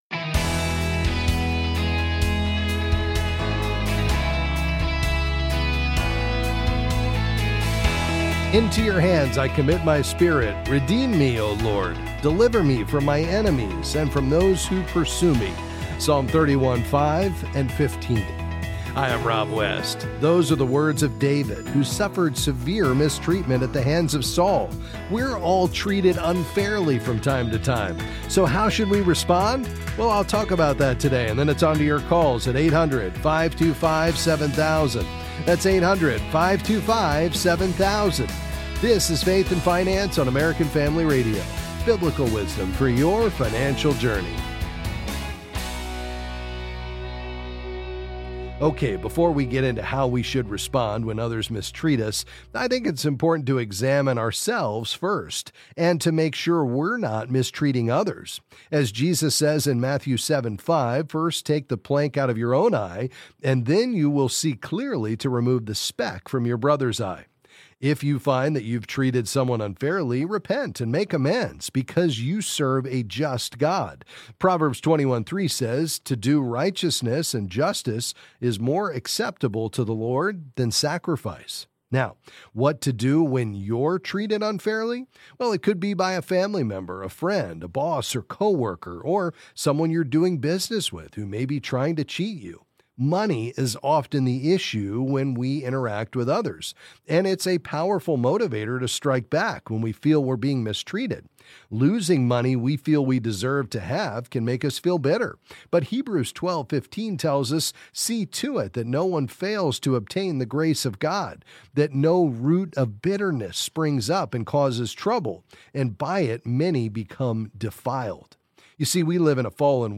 Then he answers questions on a variety of financial topics.